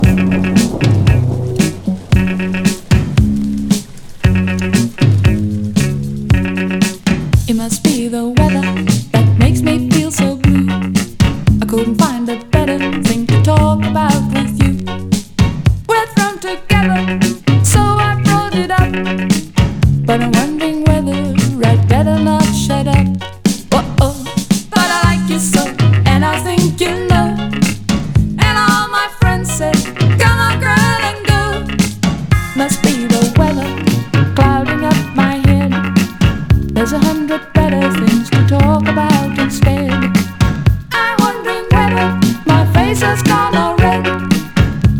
基本的にベースは、作詞作曲、ヴォーカル、コーラス、演奏と自作自演の多重録音で、宅録×80’sニューウェーブポップス。
Pop, Rock, New Wave　Netherlands　12inchレコード　33rpm　Stereo